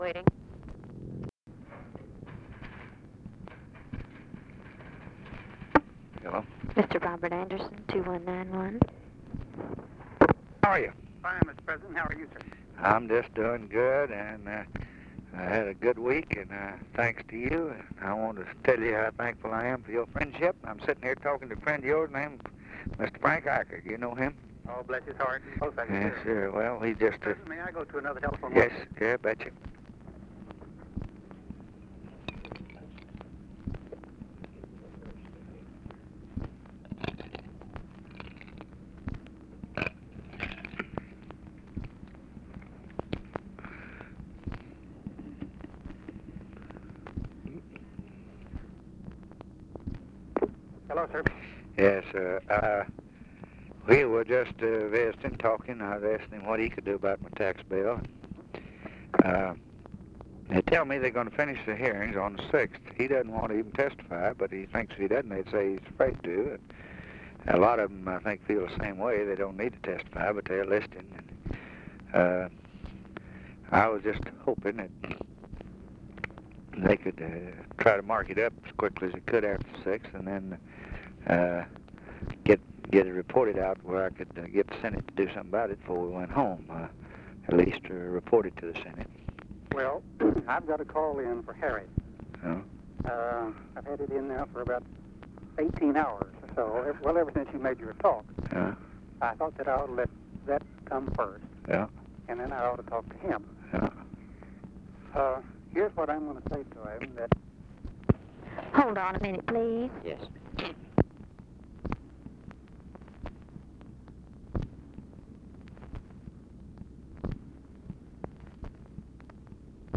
Conversation with ROBERT ANDERSON, November 30, 1963
Secret White House Tapes | Lyndon B. Johnson Presidency Conversation with ROBERT ANDERSON, November 30, 1963 Rewind 10 seconds Play/Pause Fast-forward 10 seconds 0:00 Download audio Previous Conversation with WILLIAM MCC.